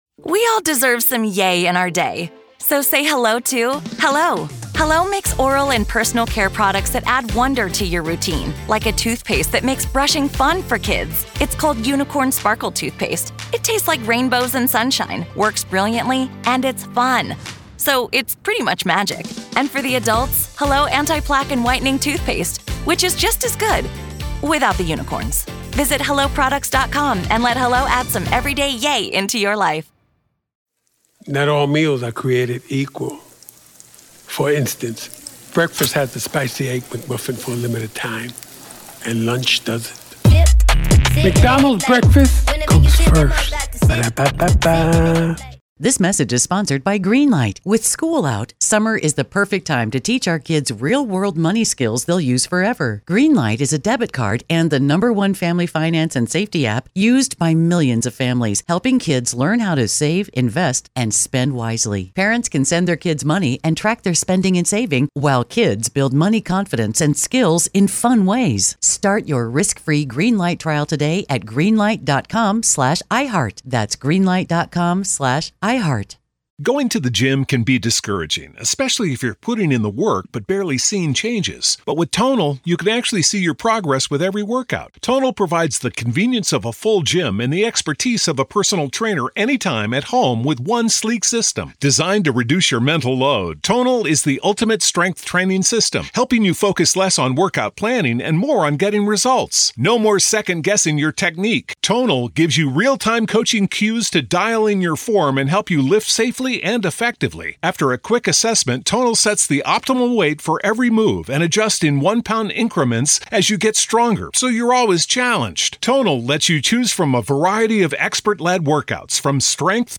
True Crime Today | Daily True Crime News & Interviews / Expert Breakdown: What Exactly IS The Jeffrey Epstein List—And What Does It Reveal?